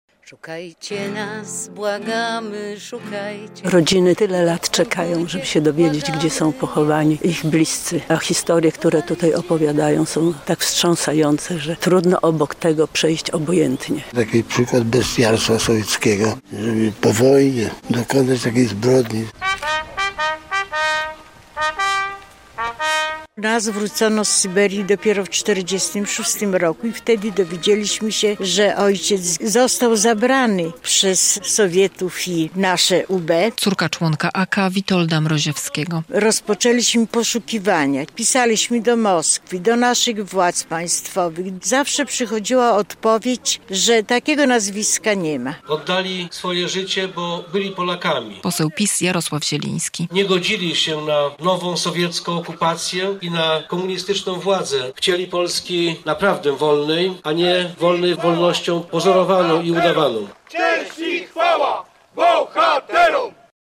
W sobotę (12.07) w Gibach odbyły się obchody 80. rocznicy obławy augustowskiej - największej zbrodni komunistycznej po II wojnie światowej na ziemiach polskich.
80. rocznica Obławy Augustowskiej - relacja
Ofiary obławy wspominał jeden z organizatorów wydarzenia, poseł Prawa i Sprawiedliwości Jarosław Zieliński.